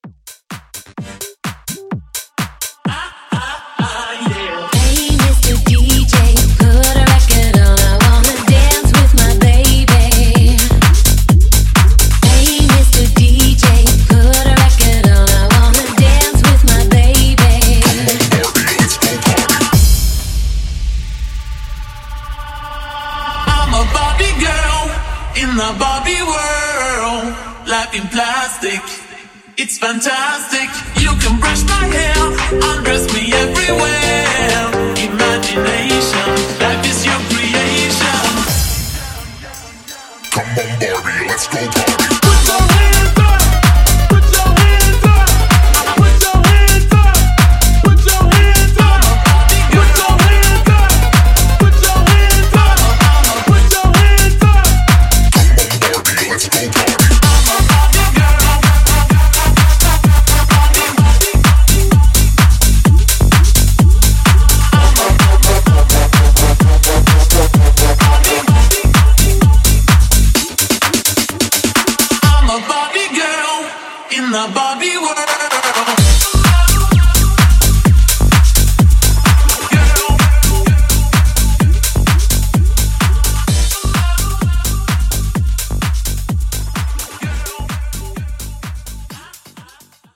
Genre: 90's
Clean BPM: 97 Time